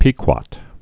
(pēkwŏt)